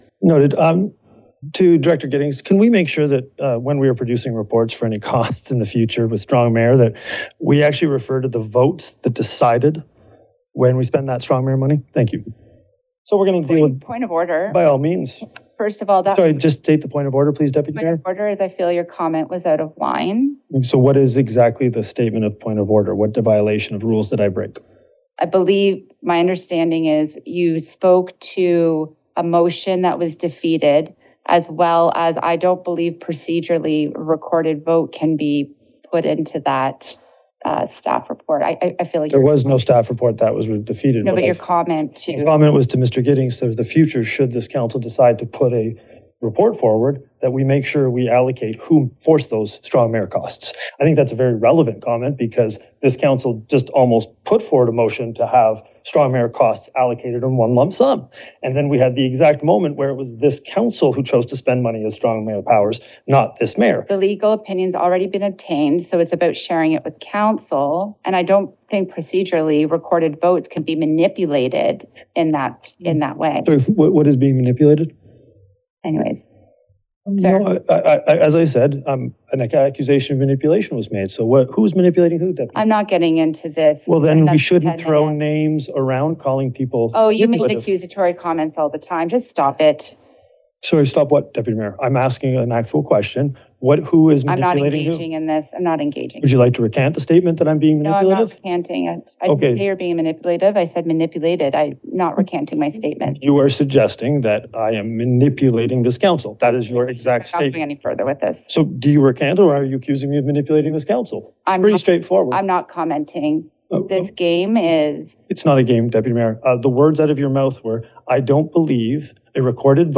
Cobourg politicians sought to clarify several of Mayor Lucas Cleveland’s initiatives under his strong mayor powers at a regular council meeting on September 24.
Cobourg council debates a motion by Deputy Mayor Nicole Beatty on its September 25, 2025, meeting.
The mayor argued vigorously, defending the public nature of his expenses, the detailed system used to monitor his budget, and the legality of requests under the strong mayor directives.